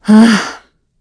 Isolet-Vox-Deny2_kr.wav